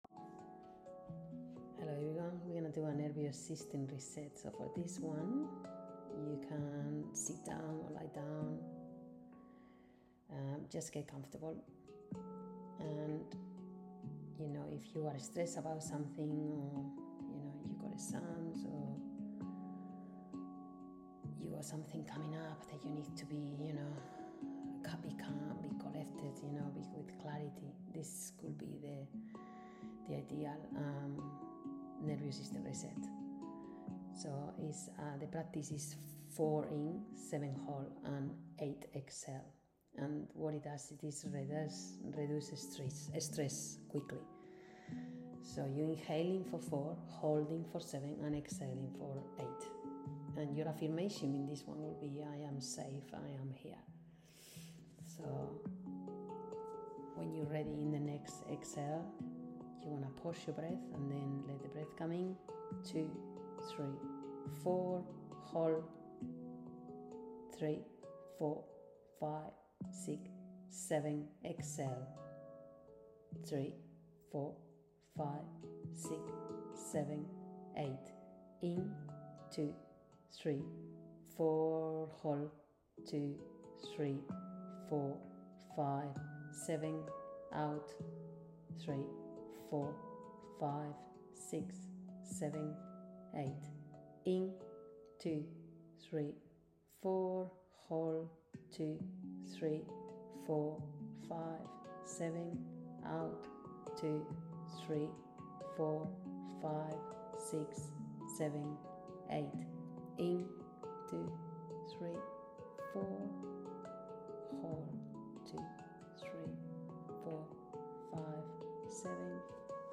Nervous System Reset Guided BreathworkMP3 • 7152KB